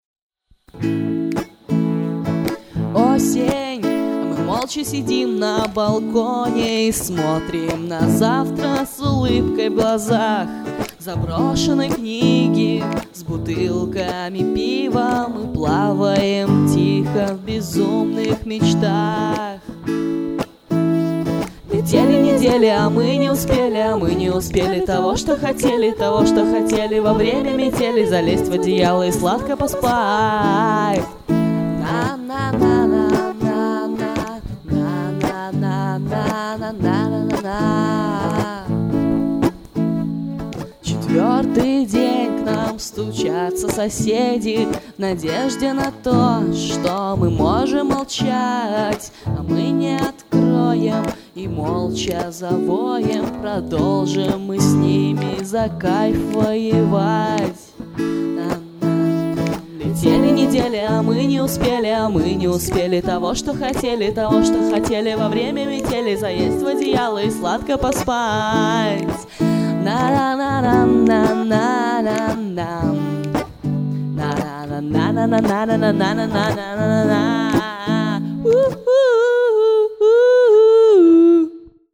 Джем-версия композиции